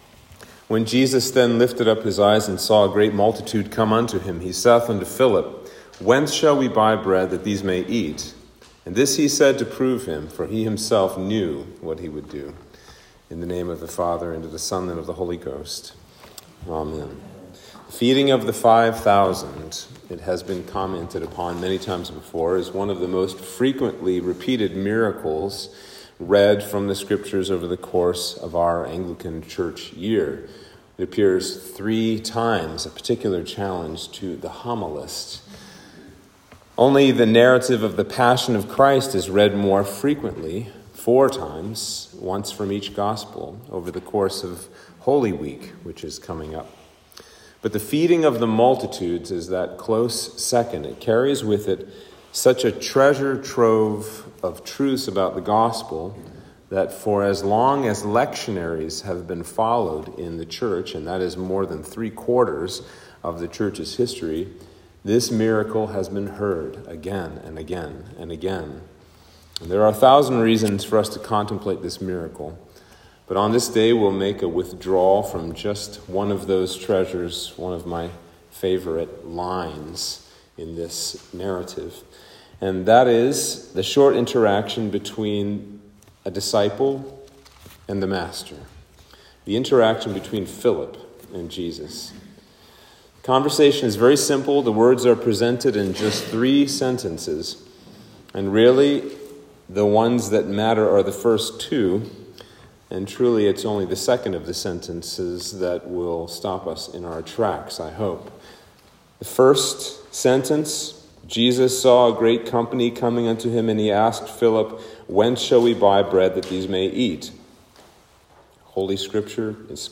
Sermon for Lent 4